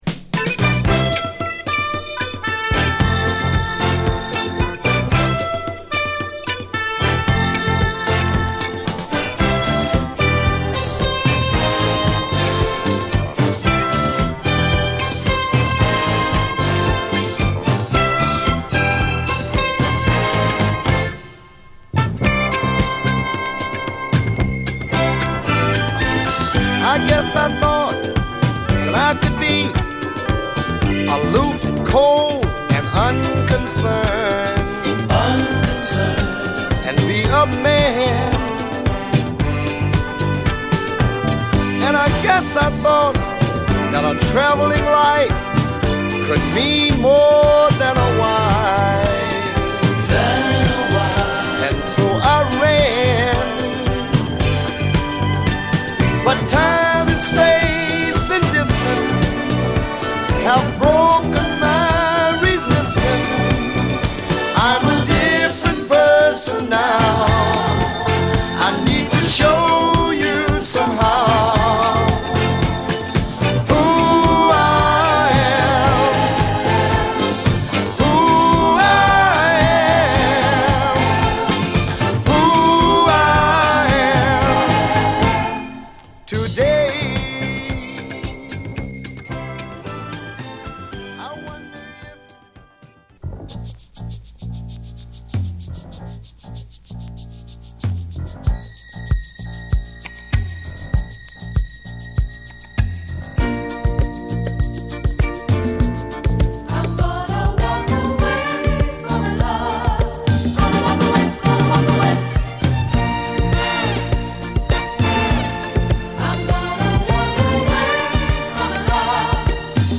ナイスソウルアルバムです！！